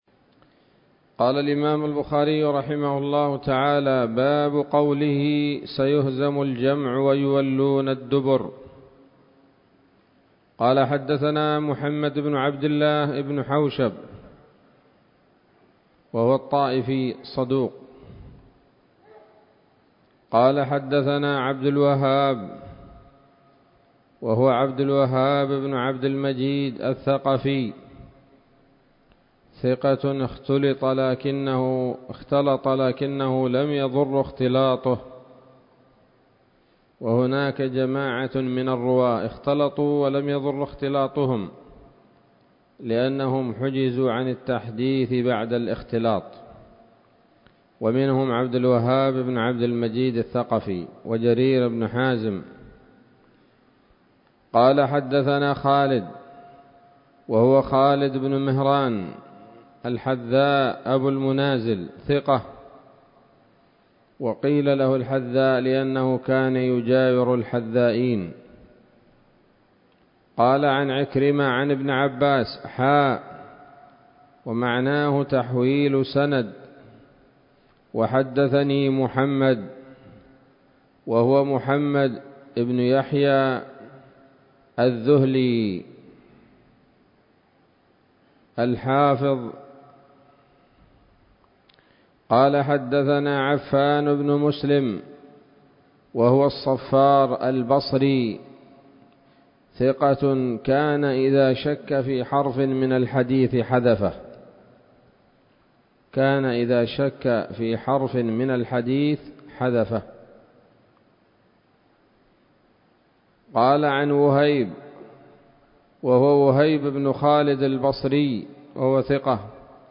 الدرس السابع والأربعون بعد المائتين من كتاب التفسير من صحيح الإمام البخاري